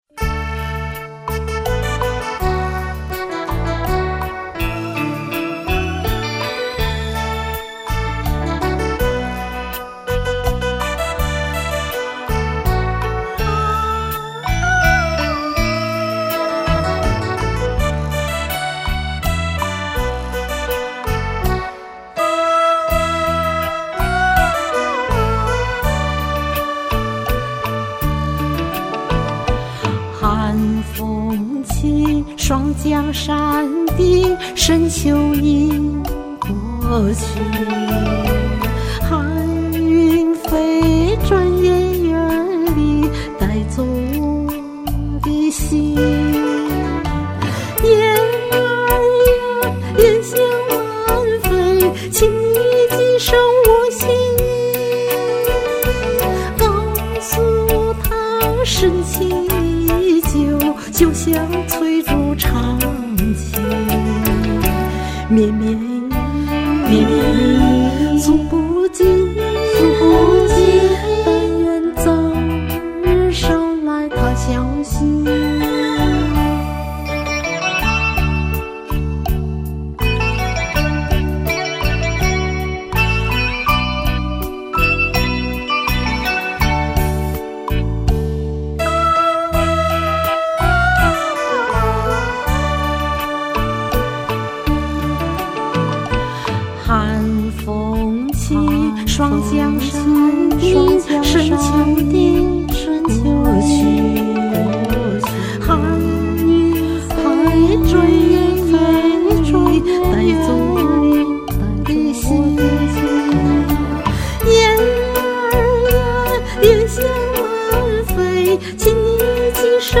唱首情歌
这一下，终于能录音了，却发现监听没了，也就是说，录音时就只能听到伴奏的声音听不到自己唱的声音。
当年注册上高山伊始，山上就有几位山友同时说我适合唱比较嗲的歌，像：江南小调、三、四十年代的靡靡之音，等等。